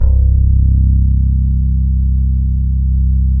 TS 10 FRET.wav